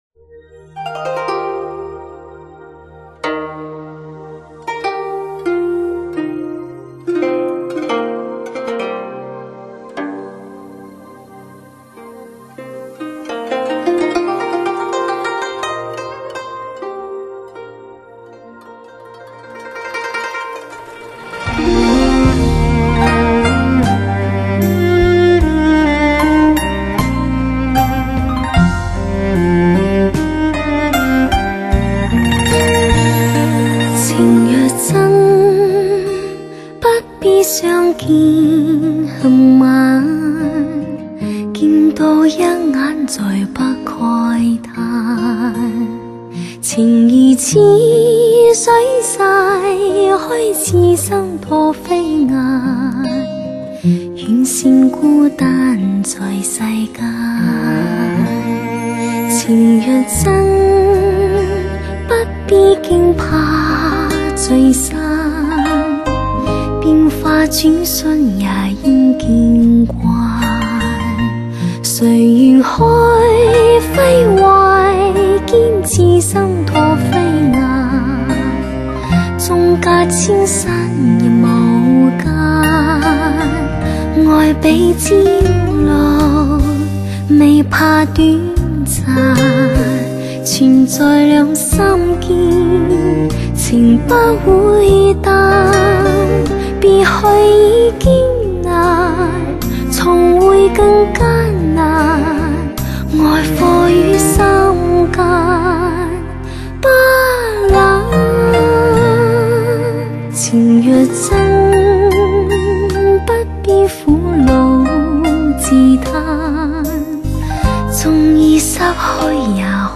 温柔婉约的清新演唱 至情至性的情感妙韵 大胆挑战你最挑剔的耳朵
人声结像度高 伴奏乐器玲珑浮突 委婉动人
小家碧玉式演唱风格 甜美柔情 天生就有叫人一听上瘾的魅力